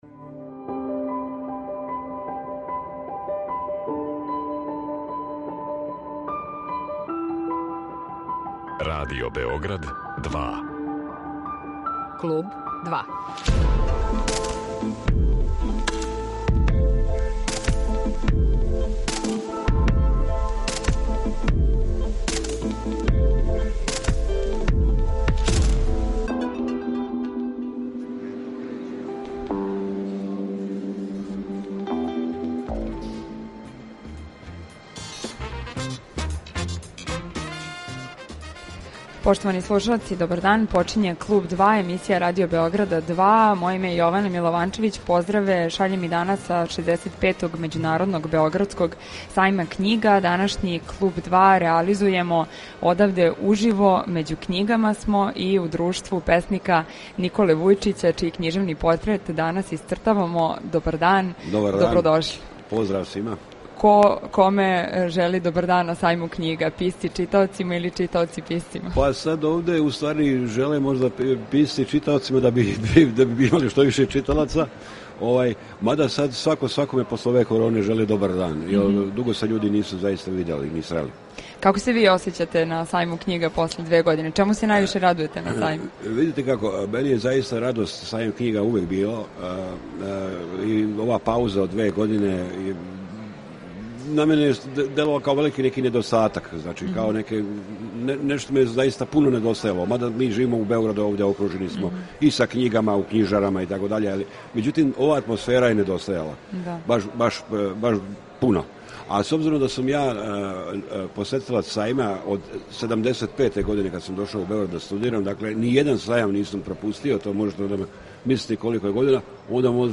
који реализујемо уживо са 65. Међународног београдског сајма књига
Разговор води